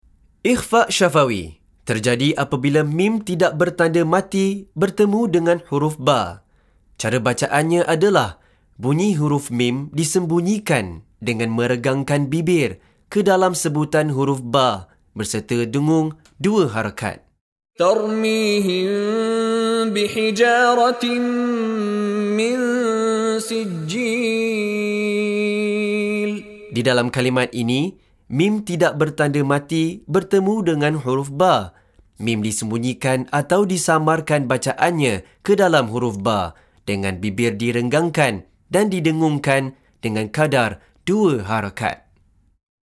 Penerangan Hukum + Contoh Bacaan dari Sheikh Mishary Rashid Al-Afasy